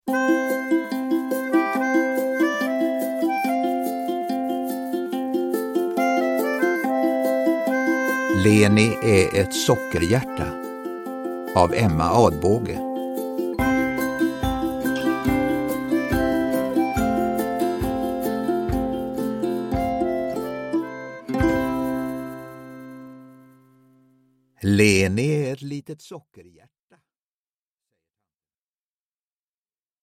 Leni är ett sockerhjärta – Ljudbok – Laddas ner
Uppläsare: Reine Brynolfsson